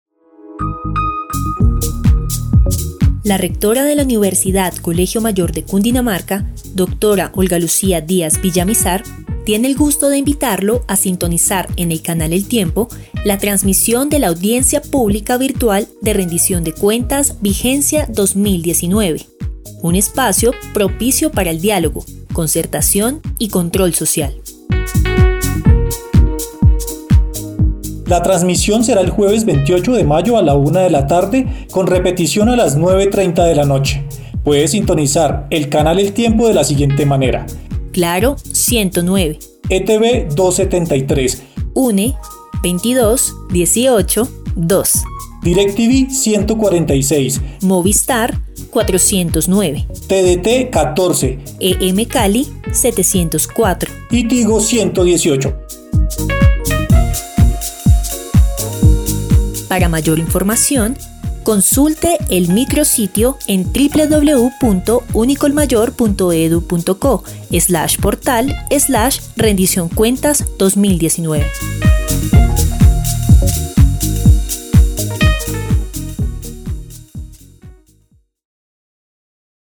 Promoción radial Preguntas frecuentes
promoradial.mp3